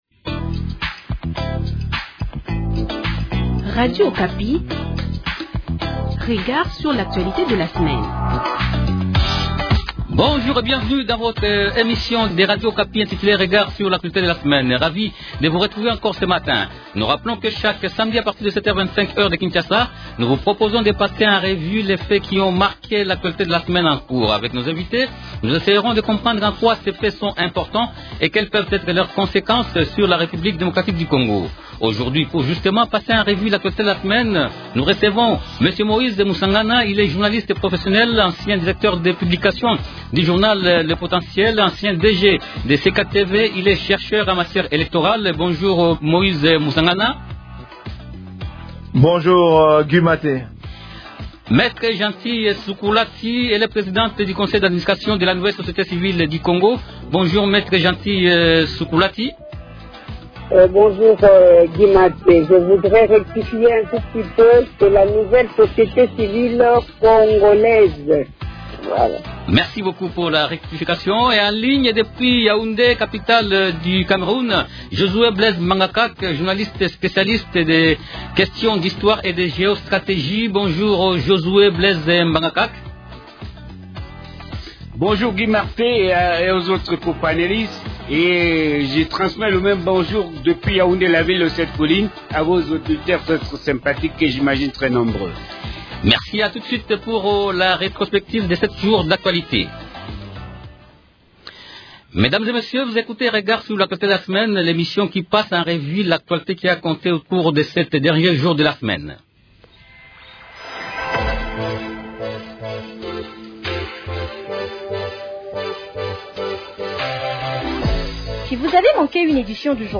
-Et en ligne depuis Yaoundé, capitale du Cameroun